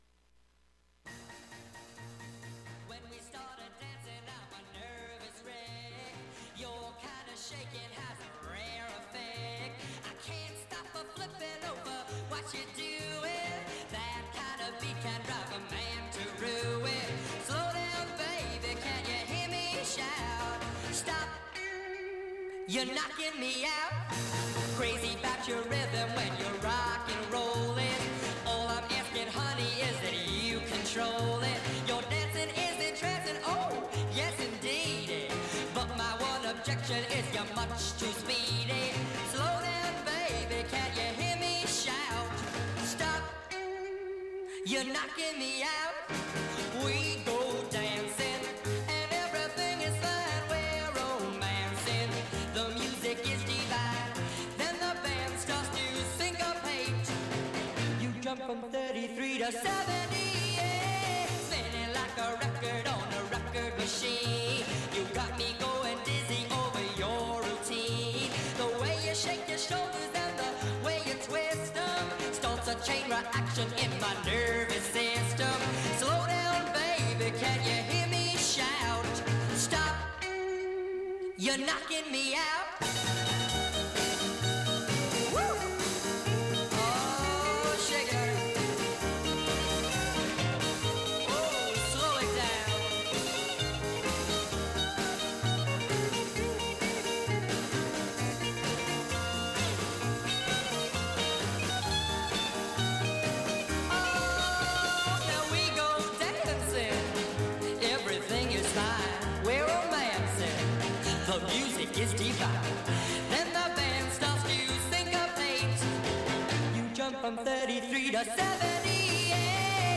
With this broadcast, Sugar Dove officially concludes 2025… but we will be back next year to bring you more genre specials! Until then, we revisited the early ’60s alongside the late ’50s, an entire decade we had never played before.